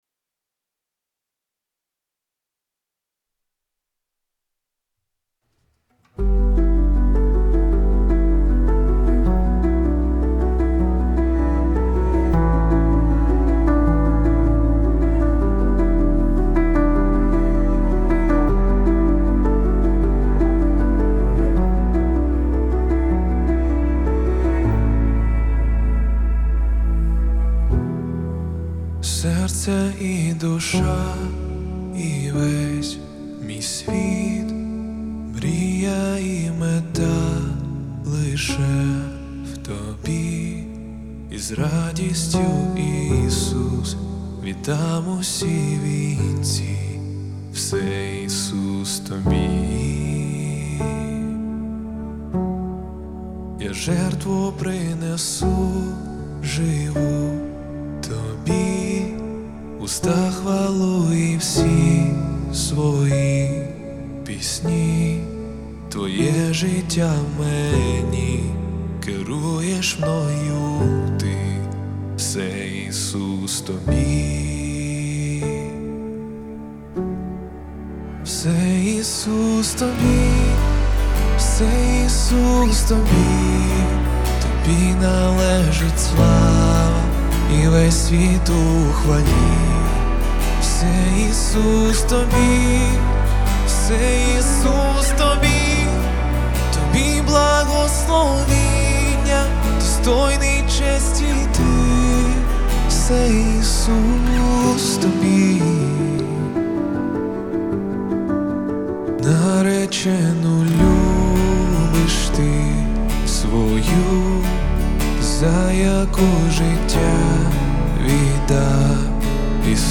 331 просмотр 308 прослушиваний 21 скачиваний BPM: 80